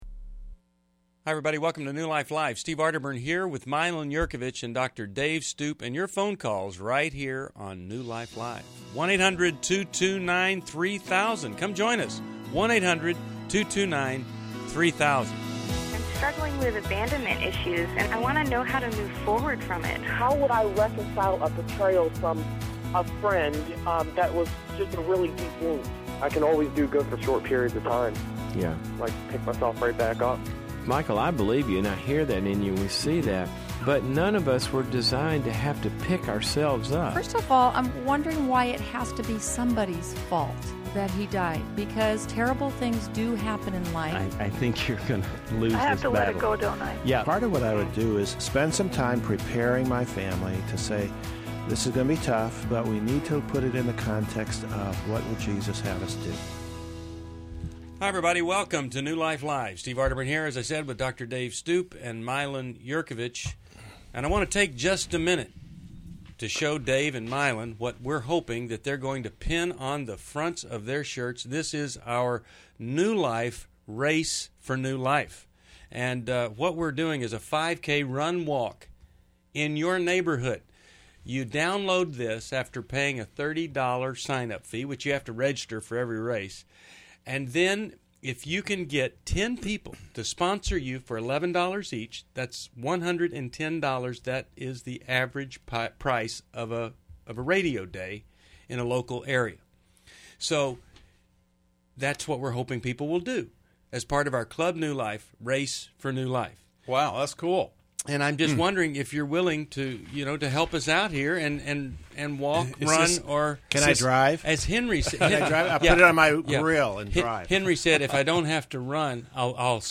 Explore themes of sexual integrity, boundaries, and family dynamics in New Life Live: March 16, 2011. Get insights from our hosts and caller questions.
Caller Questions: 1.